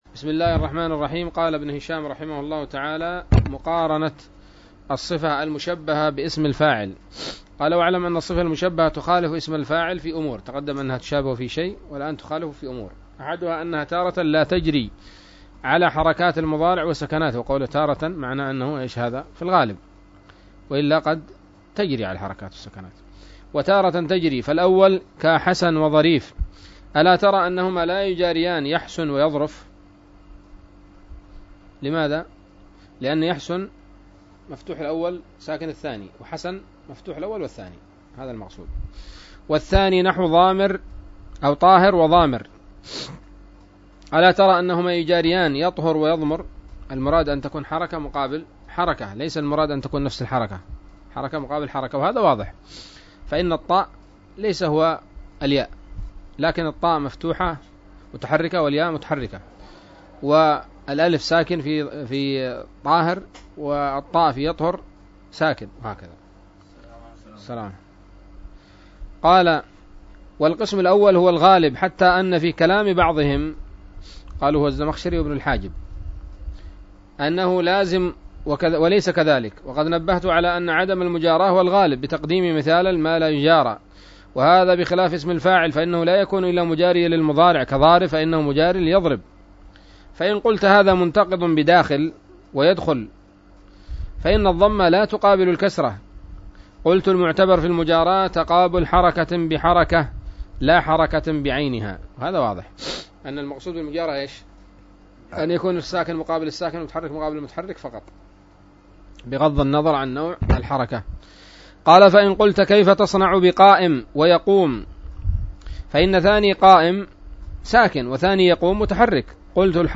الدرس الحادي عشر بعد المائة من شرح قطر الندى وبل الصدى